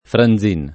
[ fran z& n ]